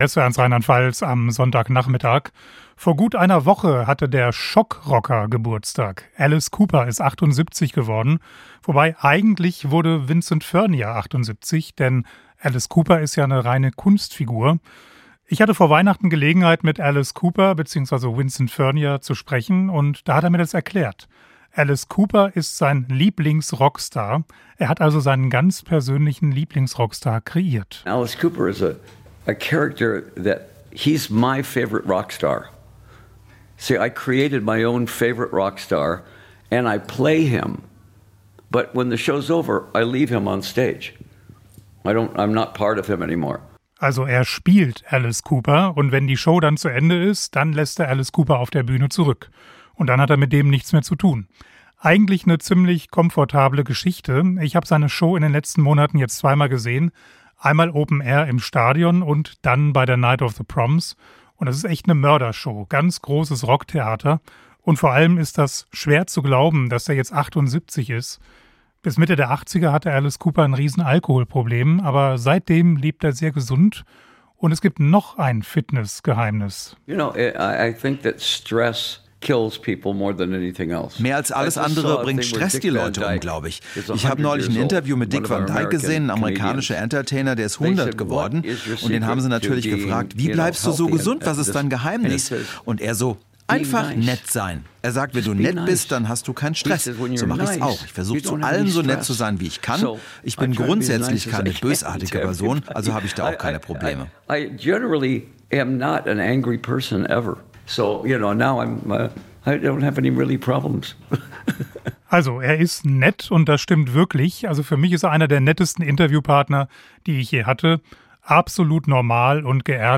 US-Musiker im SWR1 RLP Interview
Alice Cooper im SWR1 Interview über seine beiden Persönlichkeiten